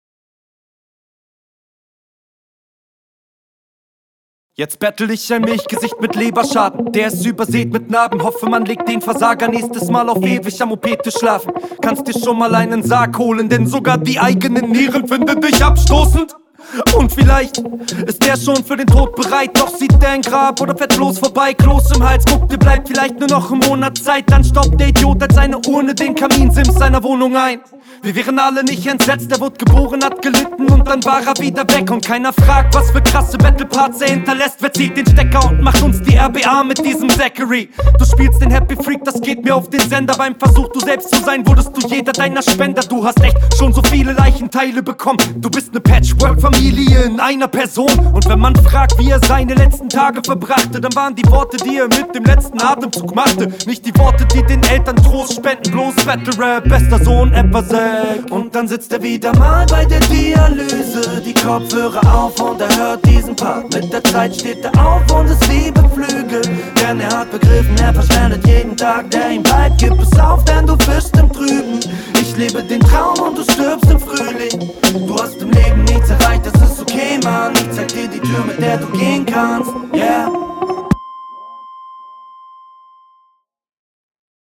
Die 4 Sekunden Stille und das abrupte einsteigen haben mich schon ein bisschen erschreckt.